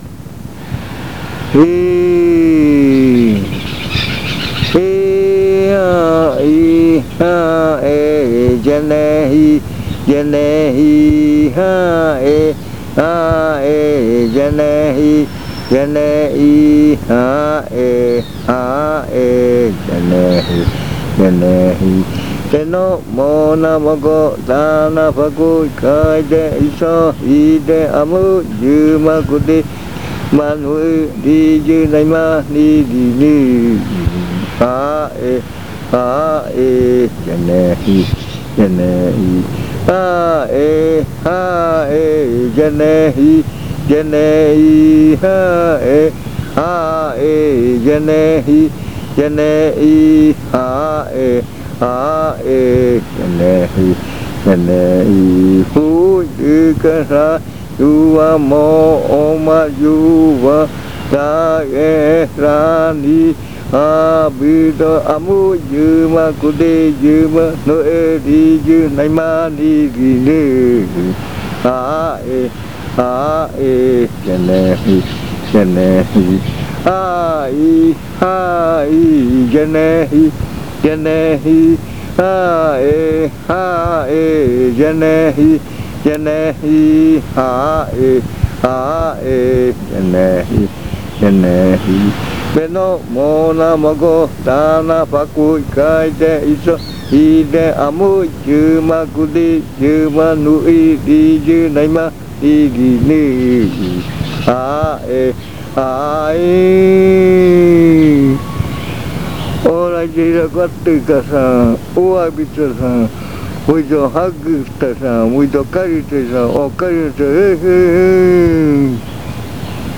Leticia, Amazonas
Canto que canta el contendor para la arrimada del baile. Canto de la libélula. Esta canción tiene adivinanza.
Chant that the ceremonial ally sings for the entry of the dance.